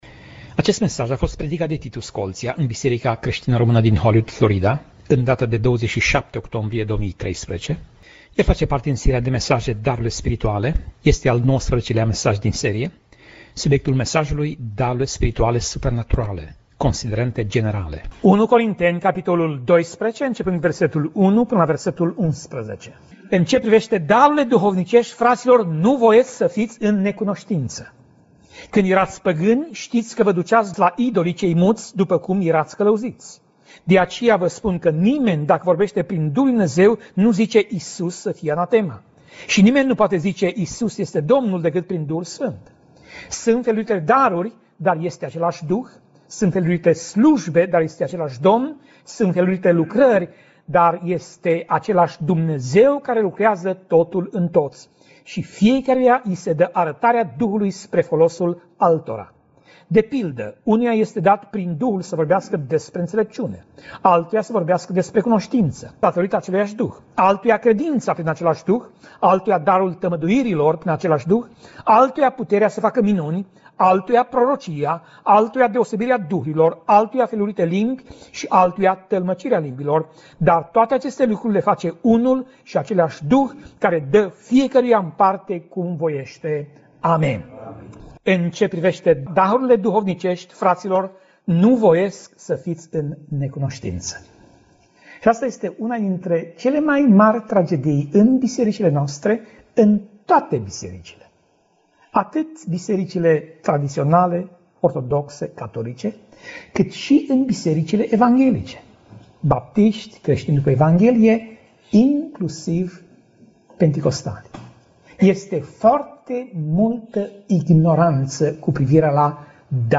Pasaj Biblie: 1 Corinteni 12:1 - 1 Corinteni 12:11 Tip Mesaj: Predica